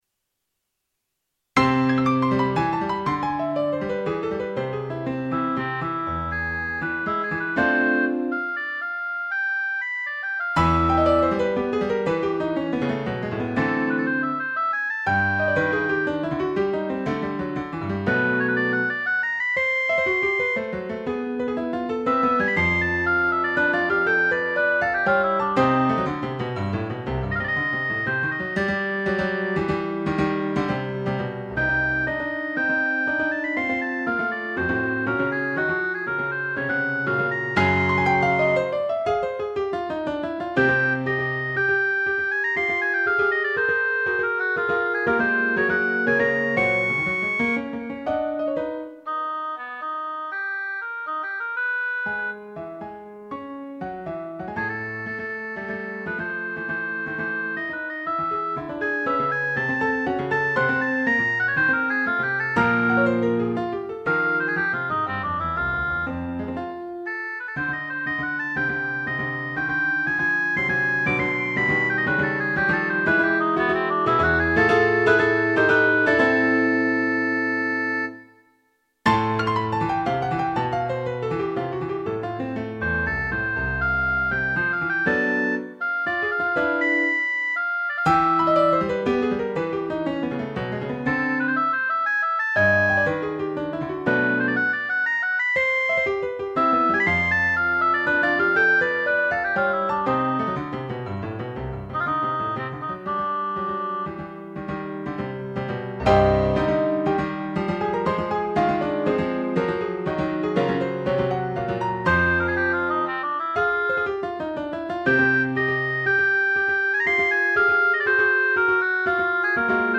iii)  a more modern piece for oboe and piano --